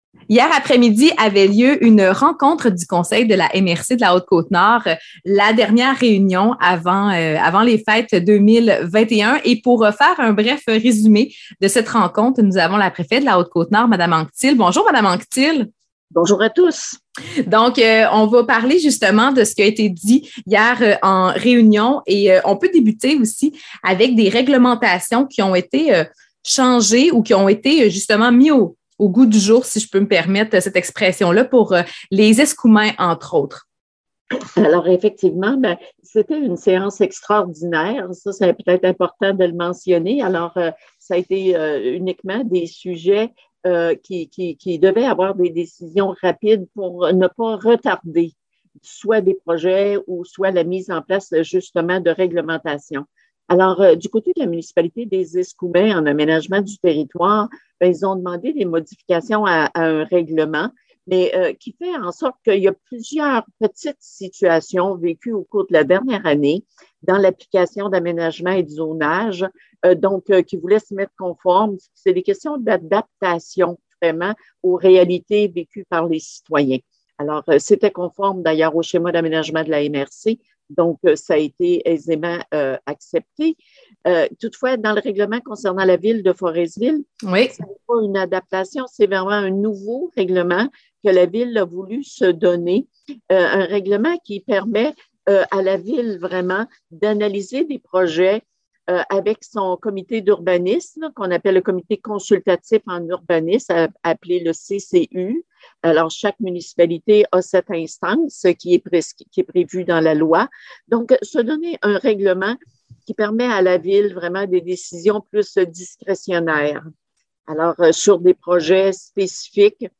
Mme Micheline Anctil, préfète de la Haute-Côte-Nord, a pris le temps de nous parler des projets en cours et de ceux qui ont été retenus en cours de séance : Télécharger Audio Précédent Article Précédent Lutte contre la COVID-19 : Vingt-trois organismes de Montréal-Nord honorés Article Suivant Réhabilitation des animaux sauvages Suivant